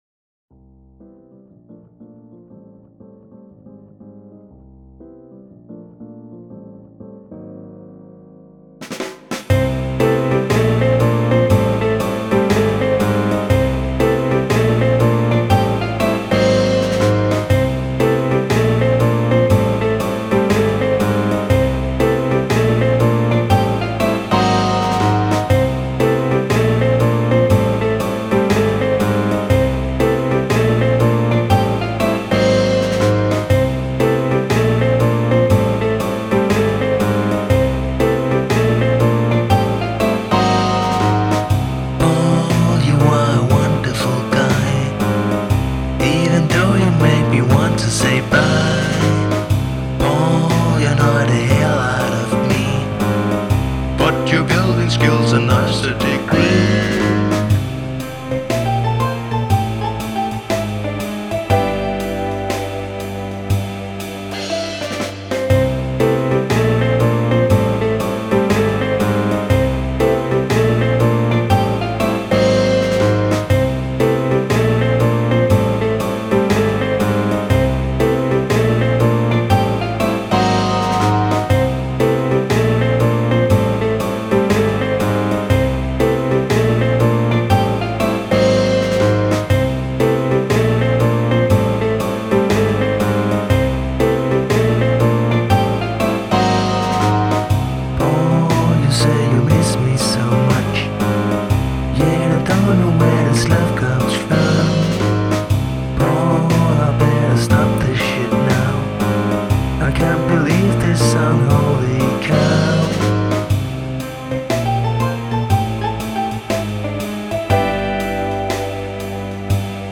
original AoS romance song